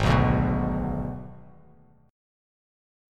G#dim chord